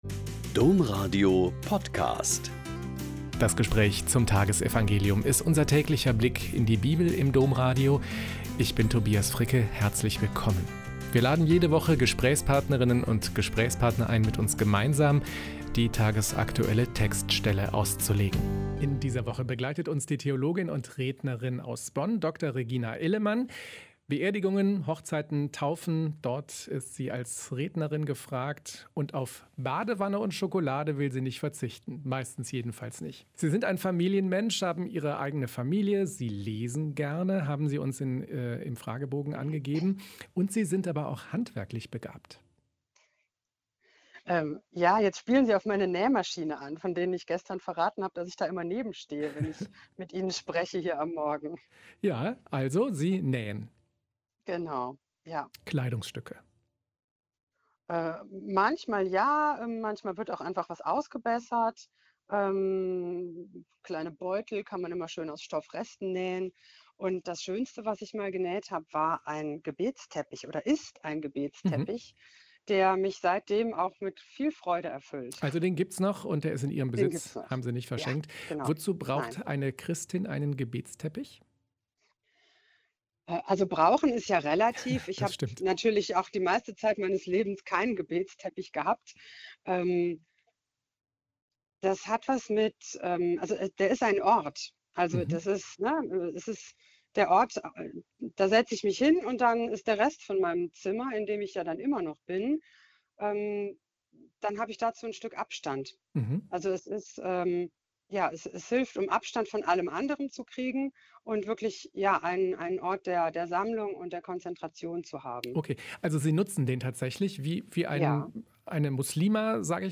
Lk 16,19-31 - Gespräch